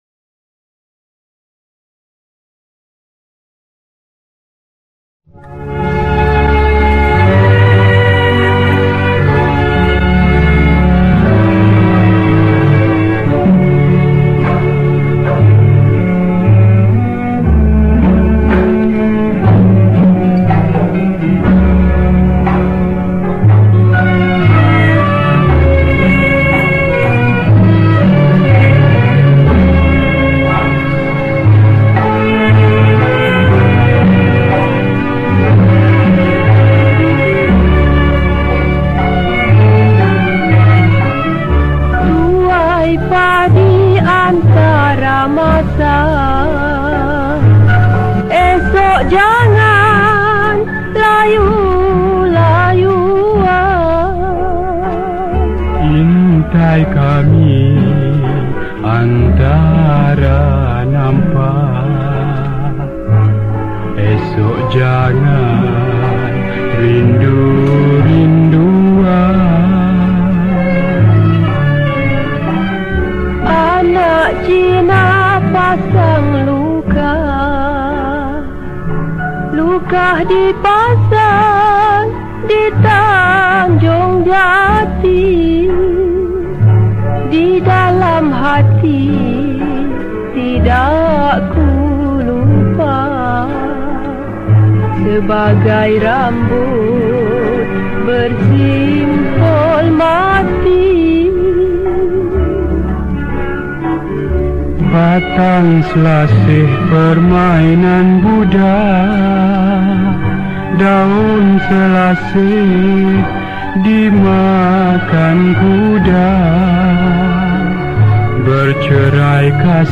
Malay Songs